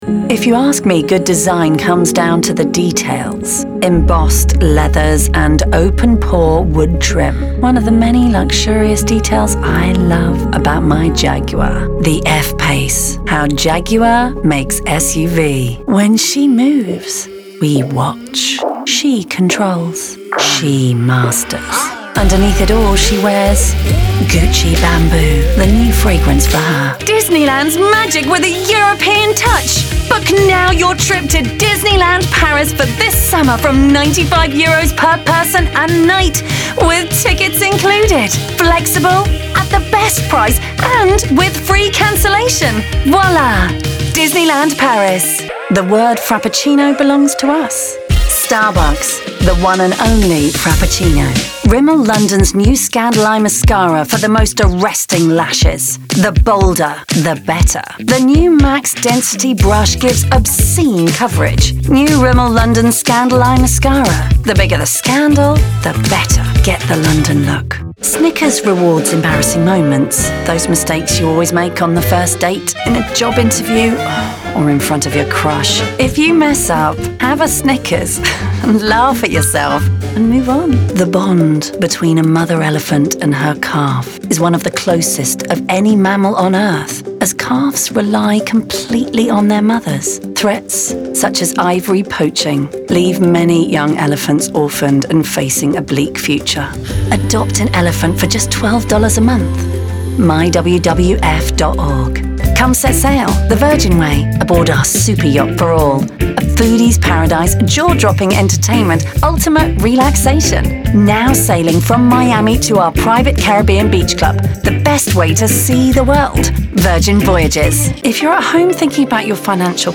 Commercial Showreel
With a warm, friendly tone and a touch of huskiness, she has over 20 years of experience across commercials, promos, corporate, documentary, animation, and video games.
Female
London
Neutral British
Bright
Confident
Friendly
Upbeat